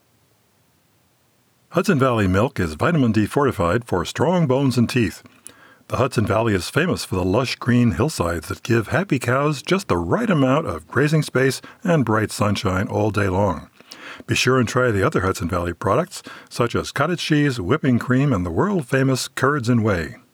I went into my quiet third bedroom just now with a simple sound recorder and cut this.
I subjected it to the AudioBook Mastering tools and then tested it with ACX Check.
I cut off knocking over the coffee cup and I did it between metrobuses going by.
The FFFF noise just barely passes.
I used an Olympus personal recorder at $120 USD.